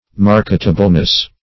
Marketableness \Mar"ket*a*ble*ness\, n.